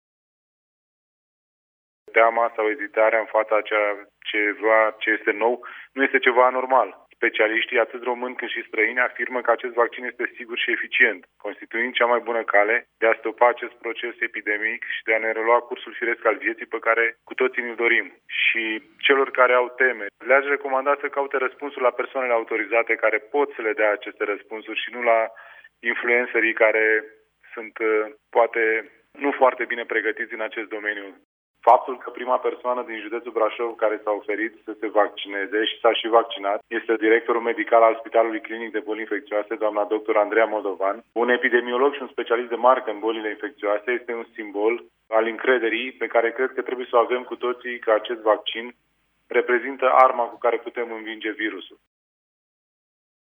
În acest context, prefectul Cătălin Văsii a recomandat, o dată în plus, braşovenilor să se documenteze din surse oficiale: